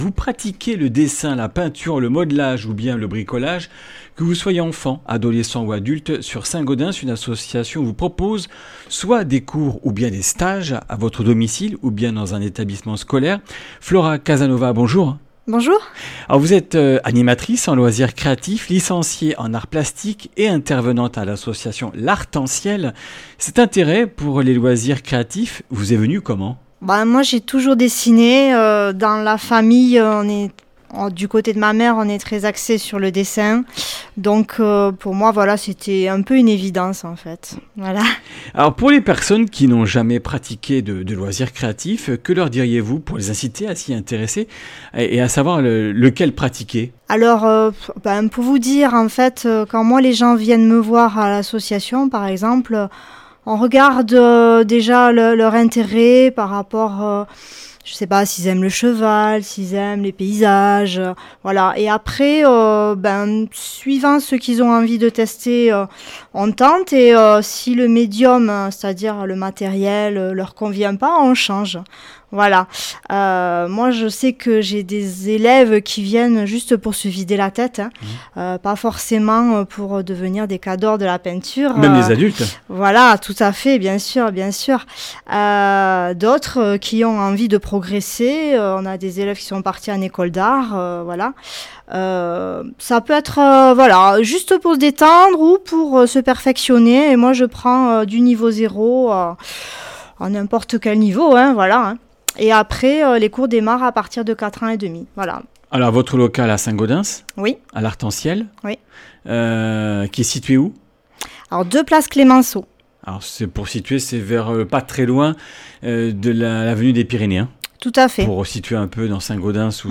Comminges Interviews du 28 oct.